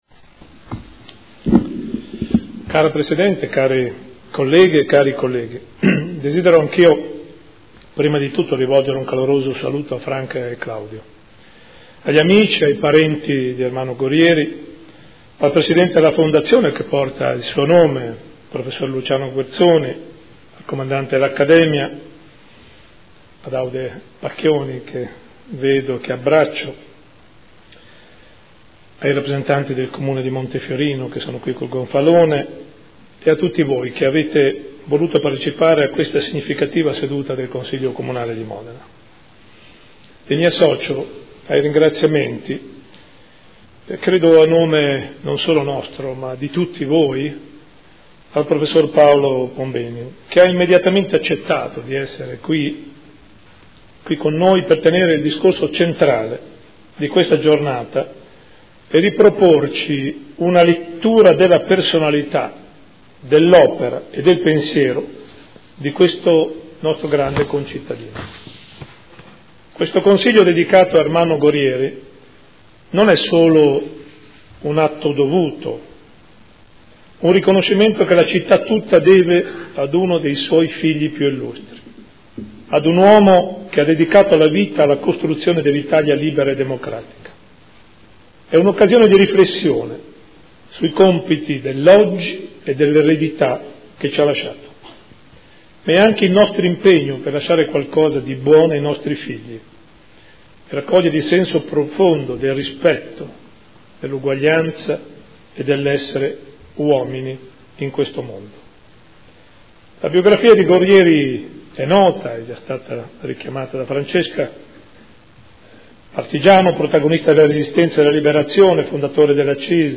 Seduta del 01/12/2014. Ricordo di Ermanno Gorrieri nel 10° anniversario dalla scomparsa, intervento del Sindaco